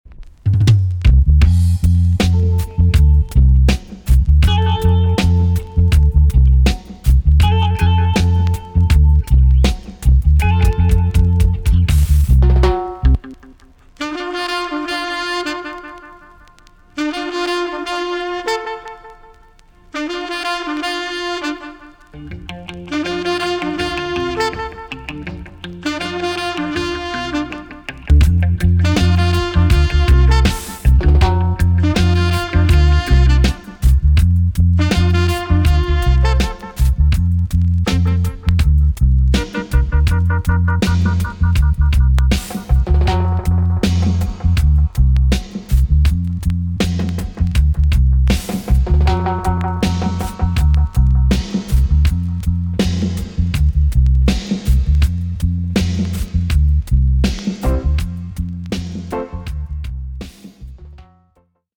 TOP >80'S 90'S DANCEHALL
B.SIDE Version
EX- 音はキレイです。